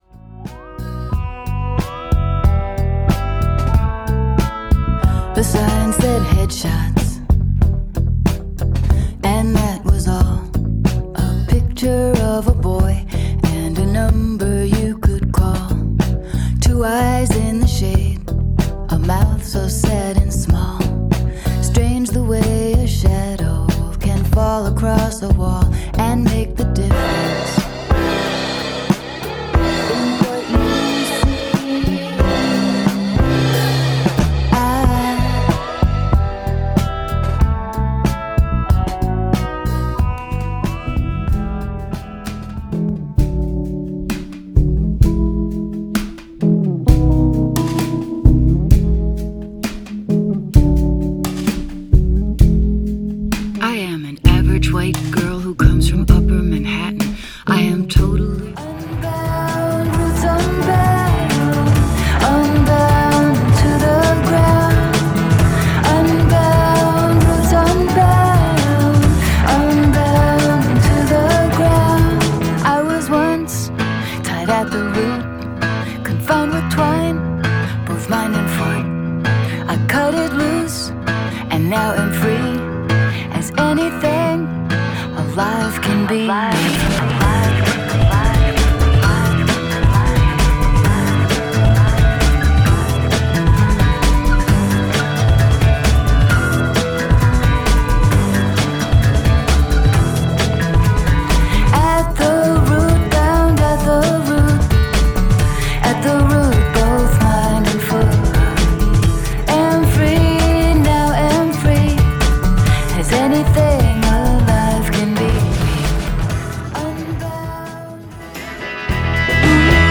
strong drum and bass lines